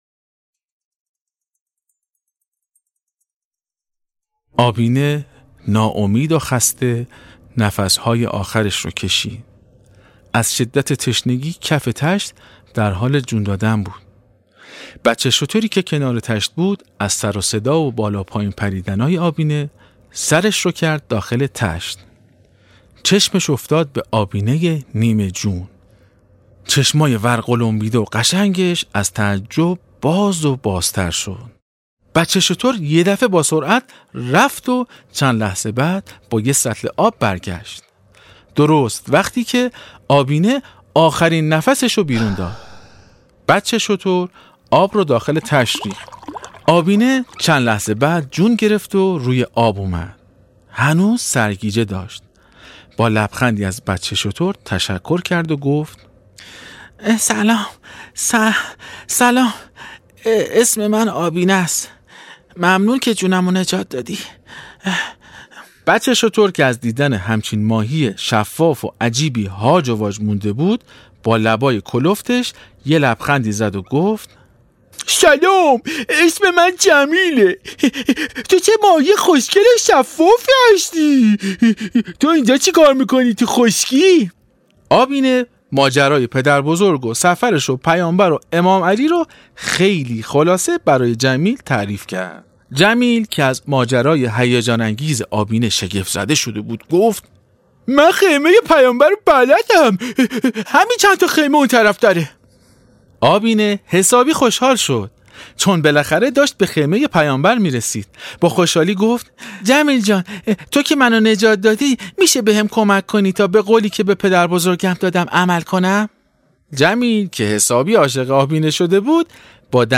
داستان ماهی ای که از یه جریان خیلی مهم برامون صحبت میکنه. باهم قسمت هفتم از داستان صوتی آبینه رو بشنویم.